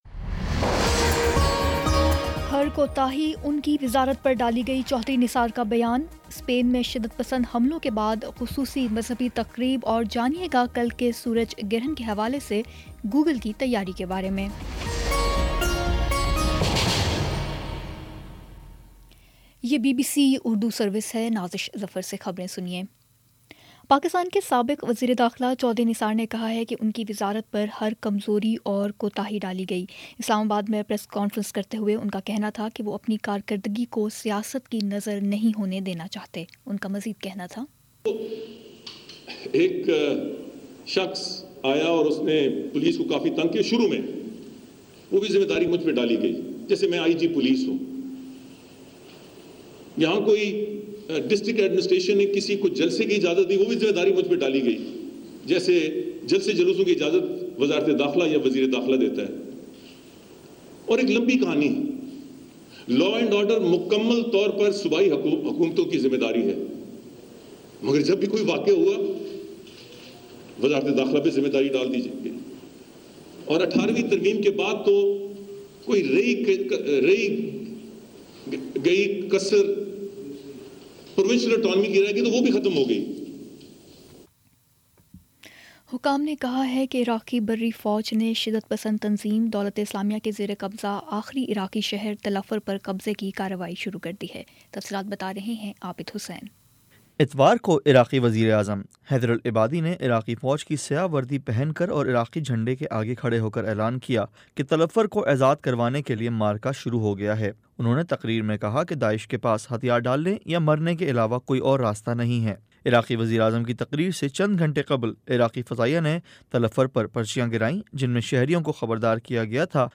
اگست 20 : شام چھ بجے کا نیوز بُلیٹن